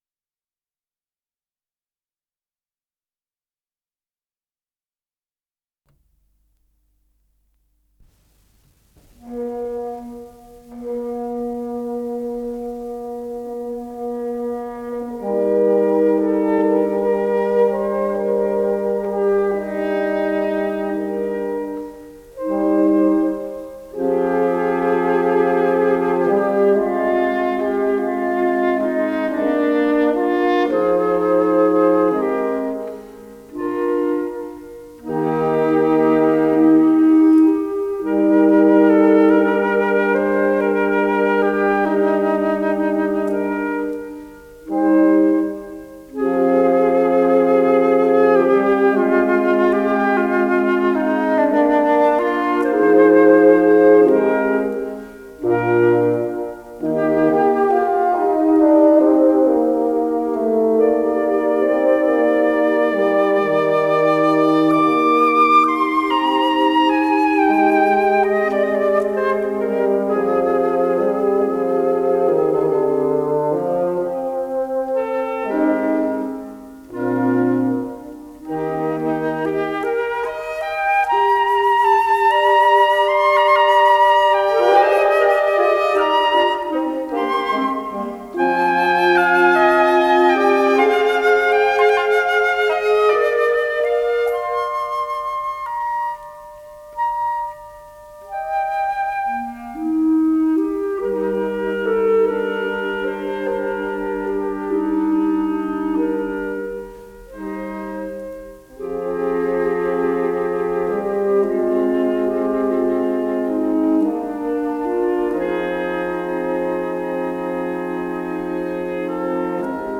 с профессиональной магнитной ленты
флейта
гобой
кларнет
валторна
фагот